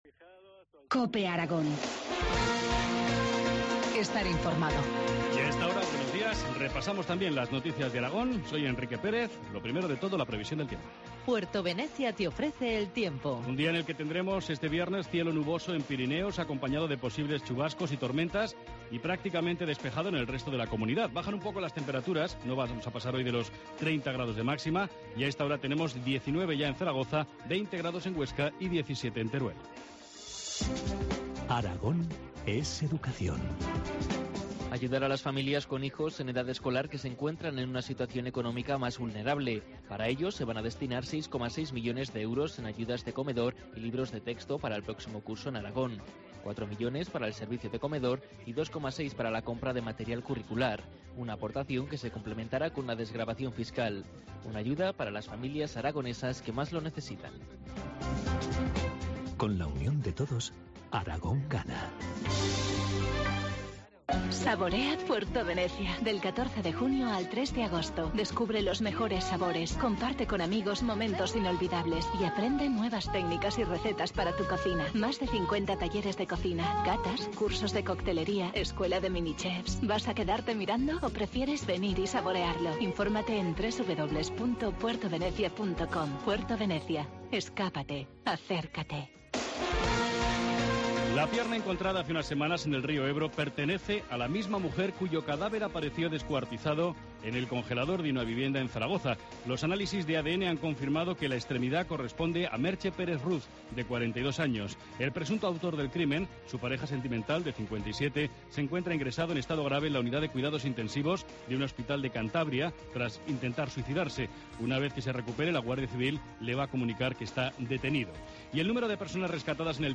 Informativo matinal, viernes 14 de junio, 8.25 horas